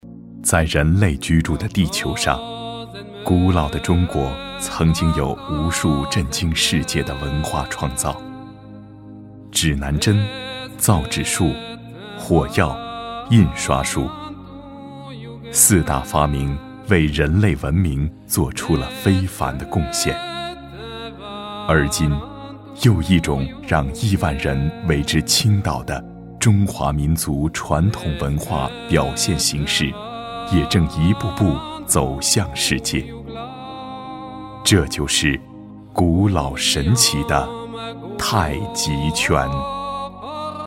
记录片男163号（中国)
娓娓道来 文化历史
大气稳重男音，擅长纪录片解说，讲述、宣传片，专题等题材。作品：古老的中国。